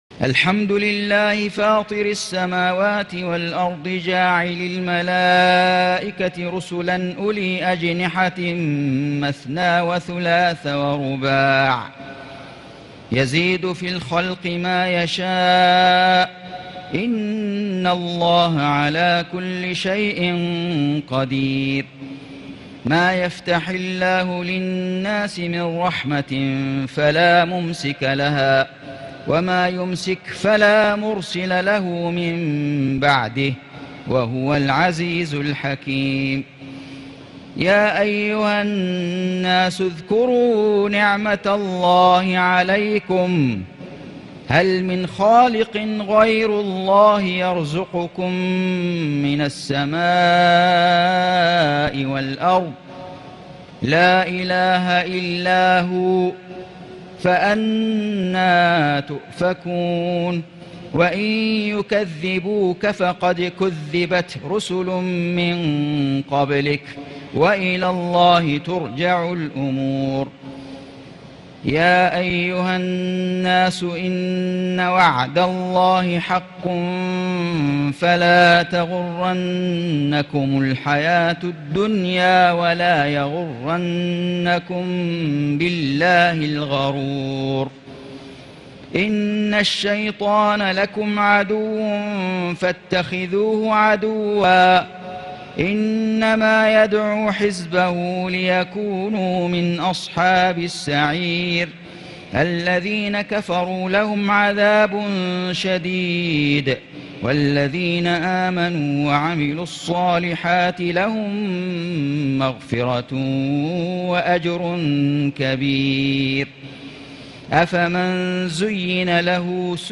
سورة فاطر > السور المكتملة للشيخ فيصل غزاوي من الحرم المكي 🕋 > السور المكتملة 🕋 > المزيد - تلاوات الحرمين